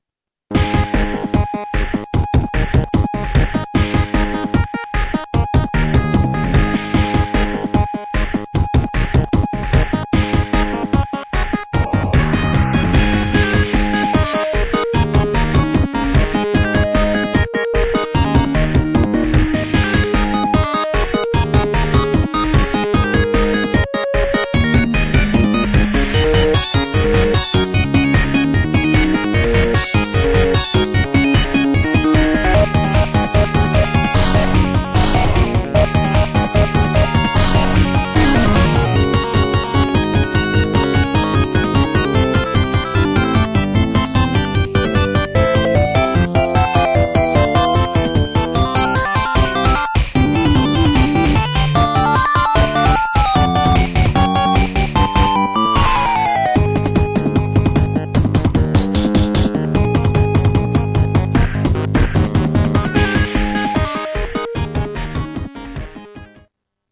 （音質　16kbps〜48kbps　モノラル）